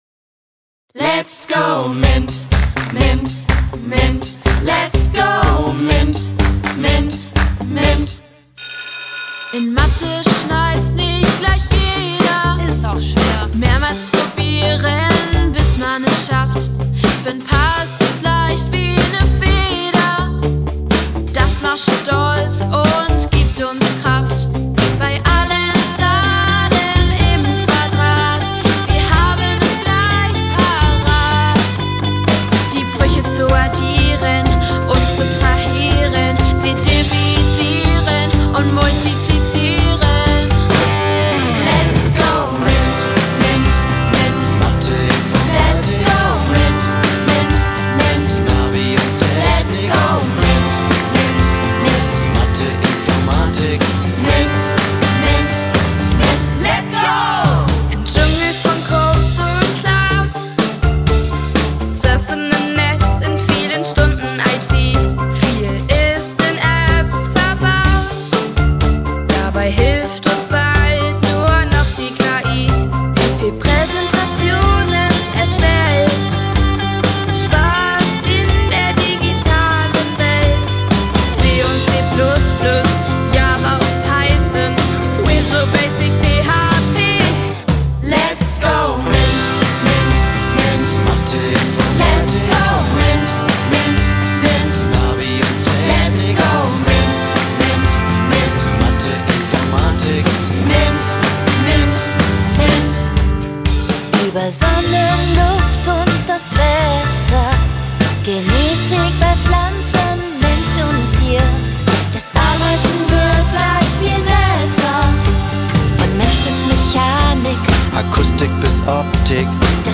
Die Schülerinnen verbrachten mehrere Tage im Tonstudio Audioprint in Kiel, um den Song einzusingen und zu mastern.